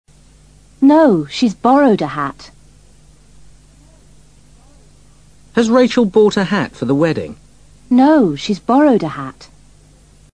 Cuando deseamos enfatizar una palabra en una oración, decimos esa palabra en voz más elevada que lo normal (more loudly) y también lo hacemos utilizando un tono más alto (a higher pitch).